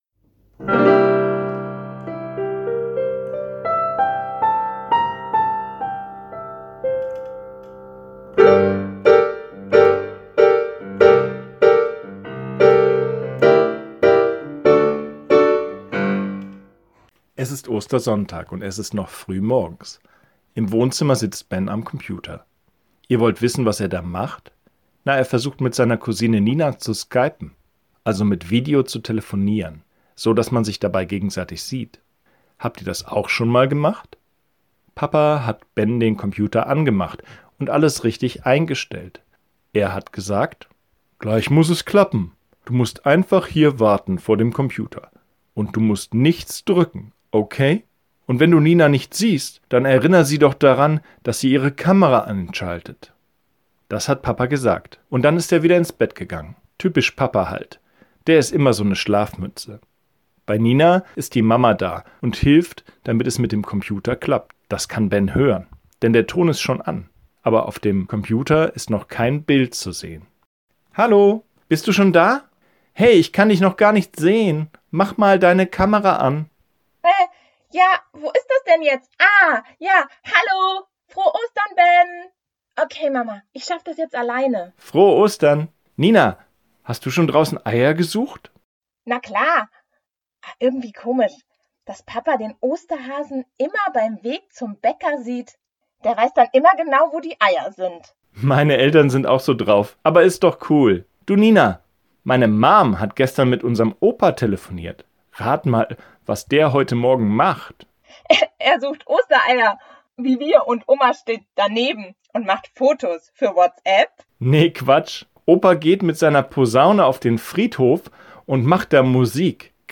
04-Ohrenkirche-Ostern-nur-geschichte.mp3